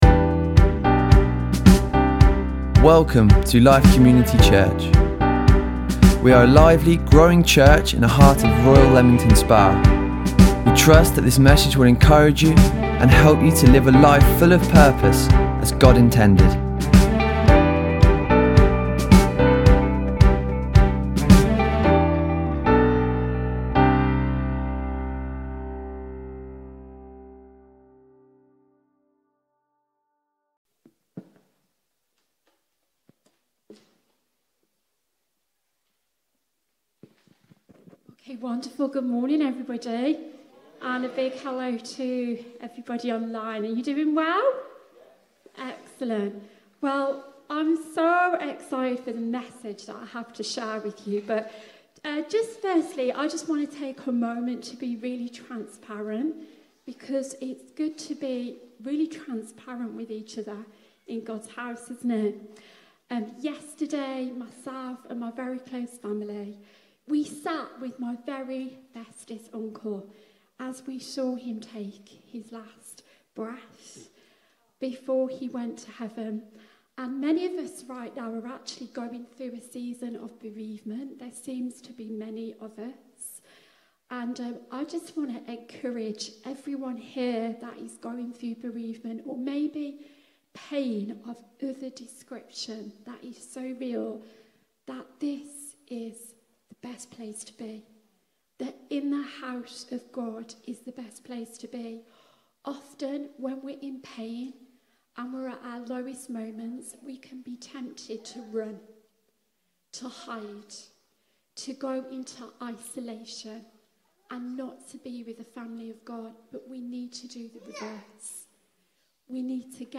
Keep up to date with our weekly podcasts recorded live at our Sunday Morning services.